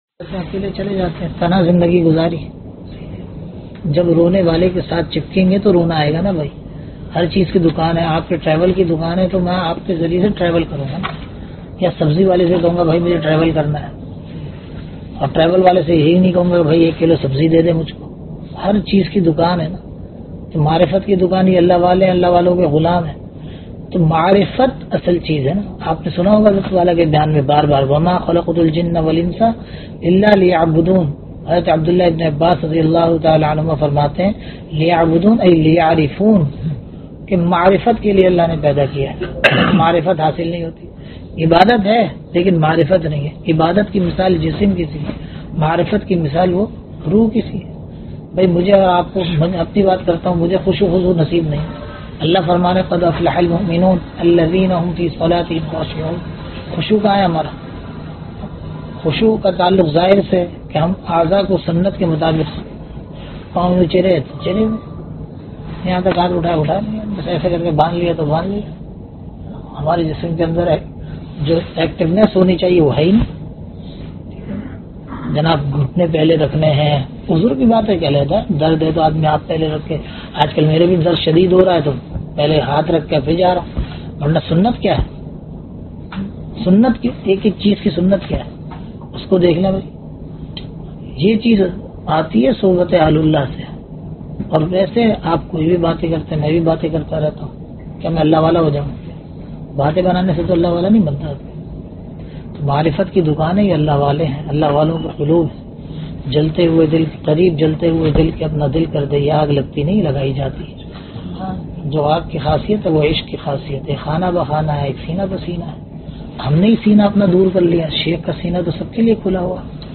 Bayanat
Suhbat e ahlullah ke samarat (subha nashte ke bad makkah mukarrma hotel)